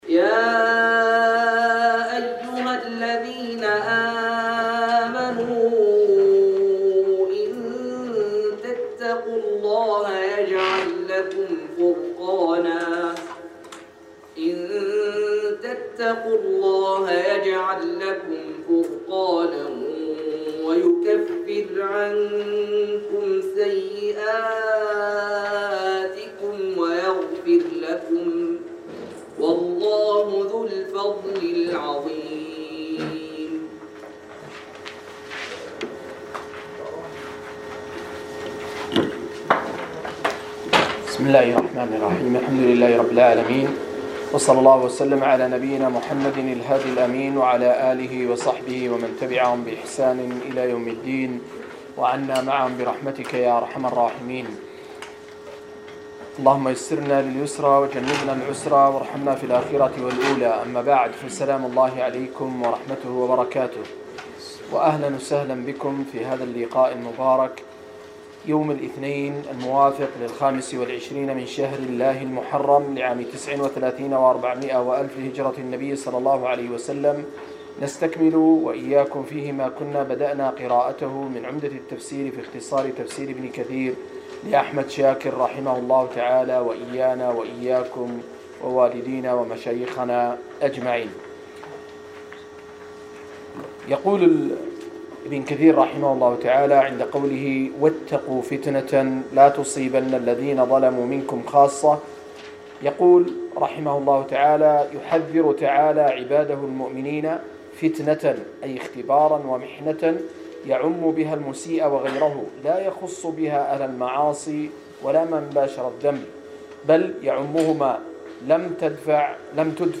168- عمدة التفسير عن الحافظ ابن كثير رحمه الله للعلامة أحمد شاكر رحمه الله – قراءة وتعليق –